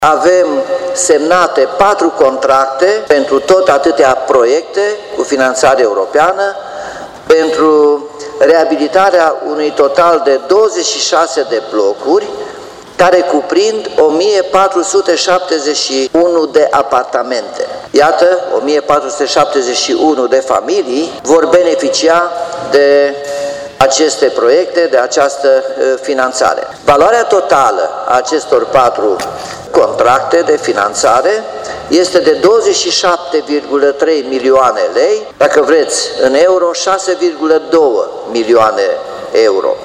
Potrivit primarului Nicolae Robu prin contractele semnate până acum vizează aproape 1.500 de familii din Timișoara.